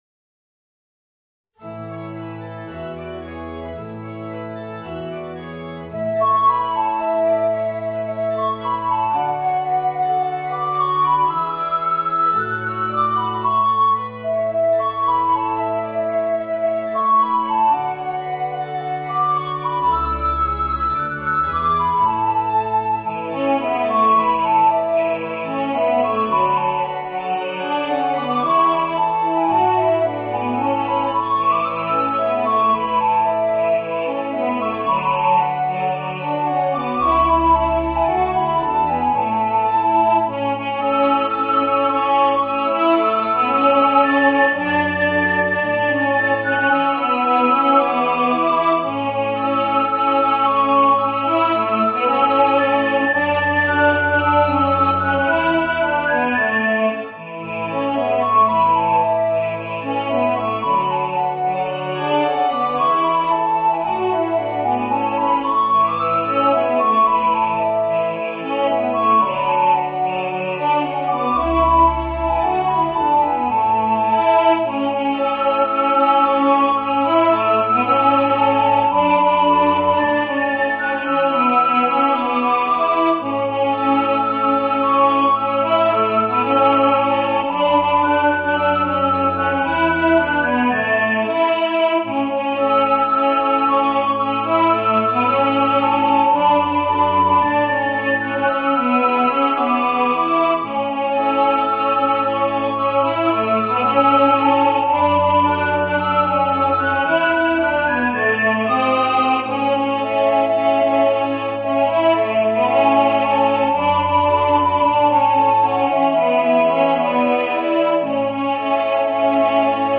Soprano Recorder, Baritone